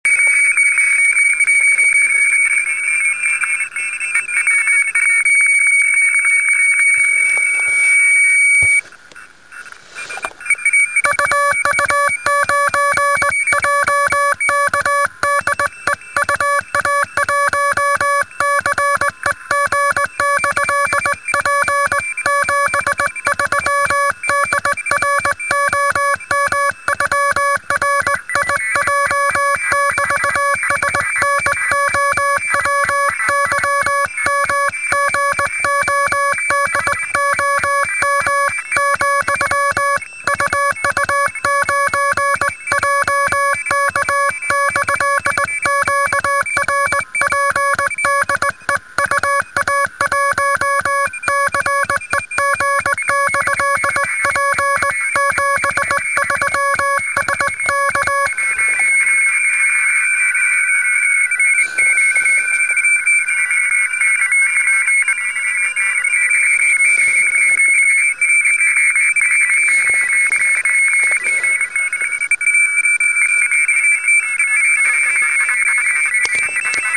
Взял ТПП на 7 мГц, QSO получилось. Частота была забита QRO станциями. С трудом, но одну связь провёл.